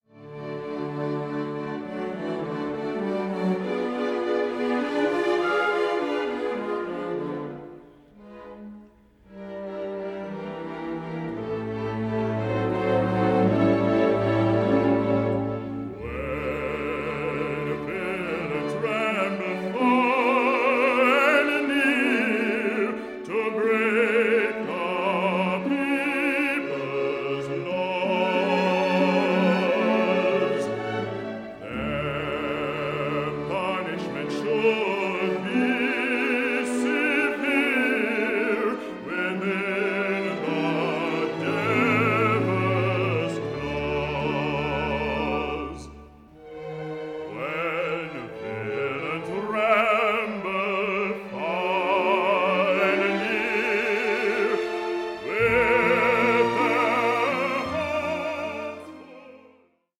Opera in Three Acts
a buoyant blend of ragtime, vaudeville and grand opera